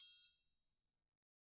Triangle3-HitM_v1_rr1_Sum.wav